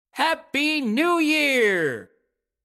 Download Happy New Year sound effect for free.